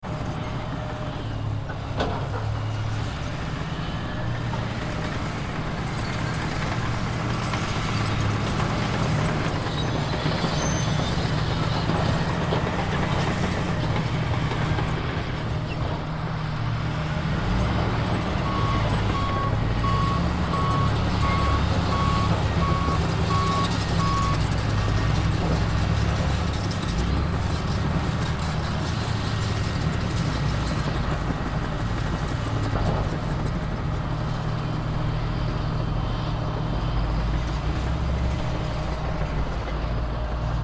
Шум гусениц и работающего двигателя бульдозера